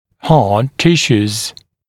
[hɑːd ‘tɪʃuːz] [-sjuːz][ха:д ‘тишу:з] [-сйу:з]твердые ткани